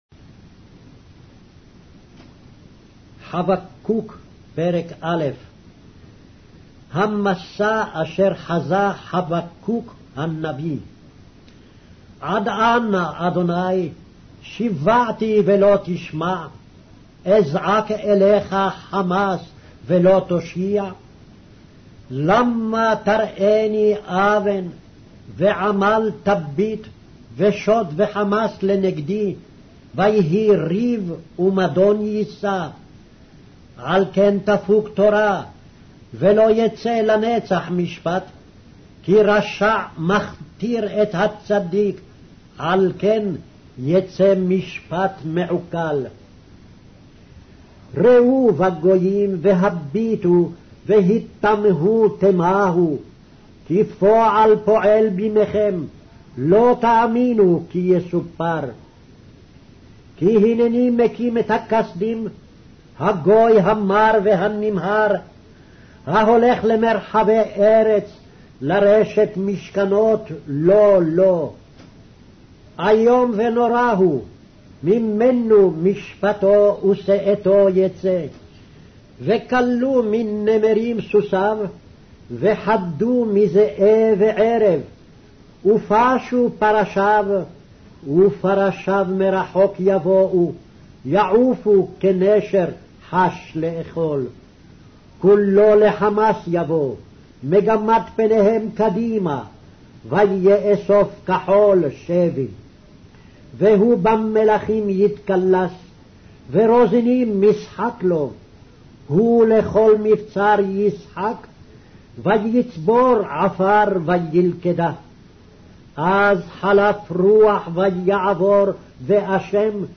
Hebrew Audio Bible - Habakkuk 2 in Mrv bible version